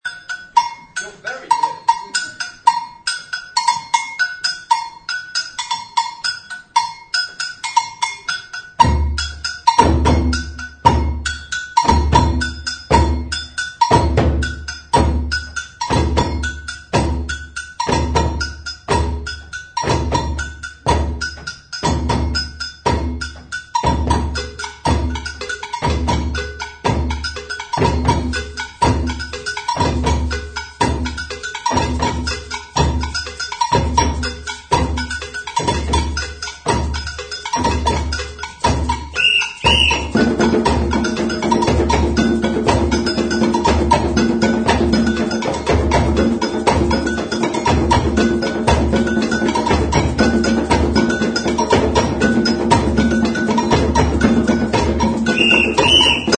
In May 2000, the Various Musical Ensembles of the school recorded a CD of the pieces they would be performing in the May 2000 concert.
to download an MP3 clip of The Percussion Group
gambiandrummingmp3.mp3